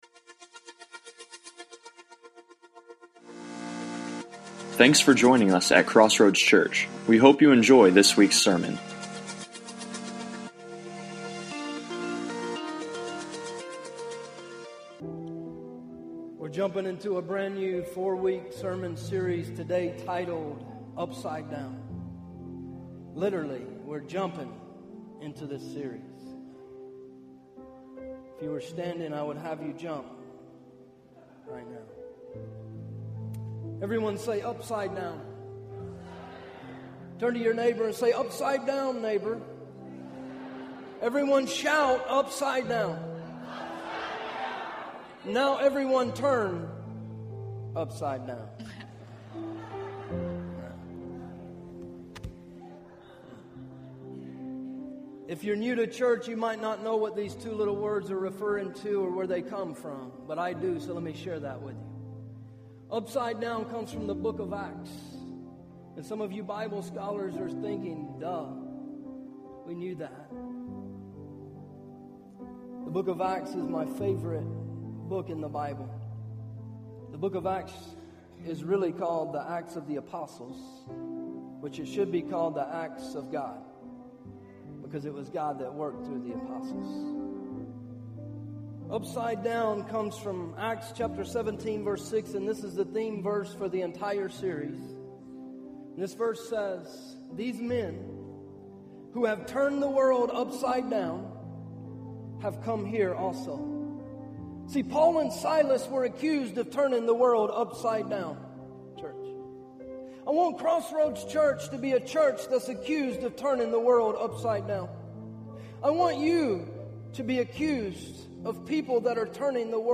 Sermons - Crossroads Church